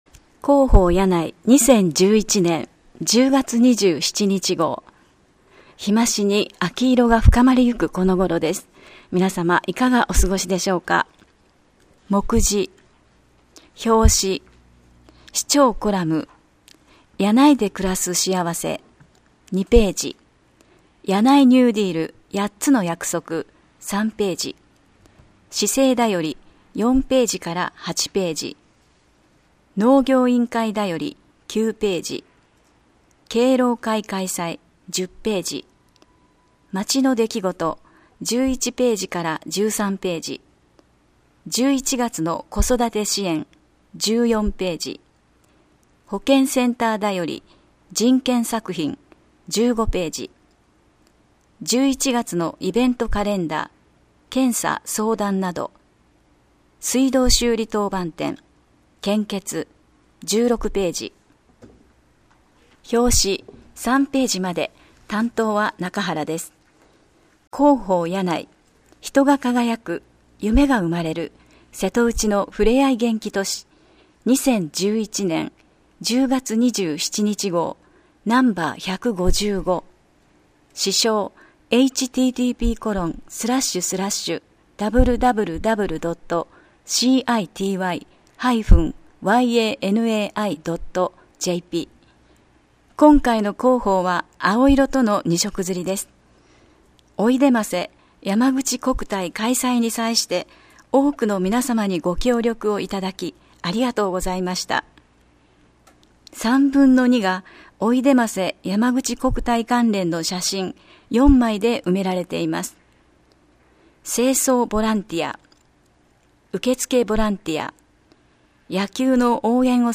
声の広報（音訳版：発行後1週間程度で利用可能）